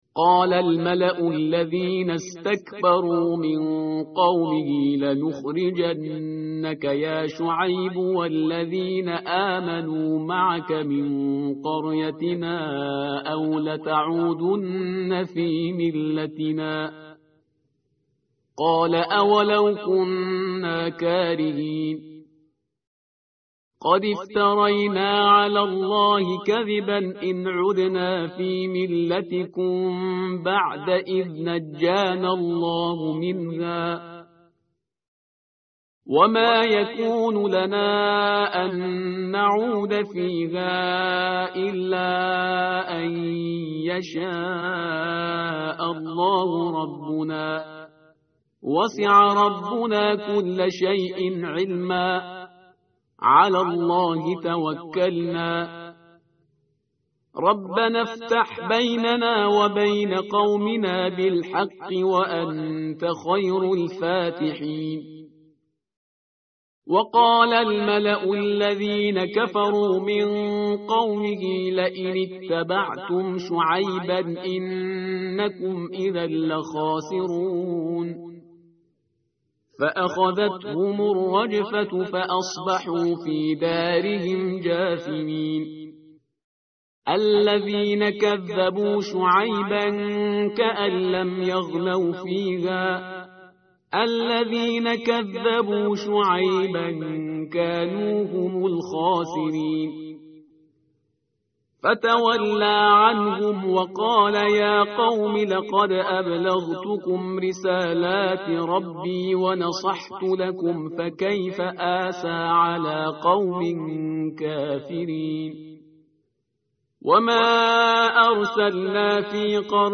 tartil_parhizgar_page_162.mp3